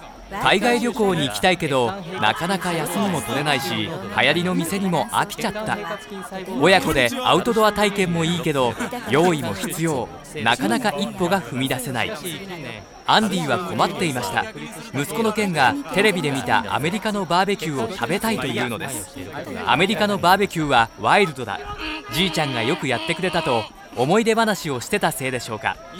スピーカーを向かい合わせて特定の音を消したOHPの光学スリットの実演をしてくれた。
opposite-phase-narration.wav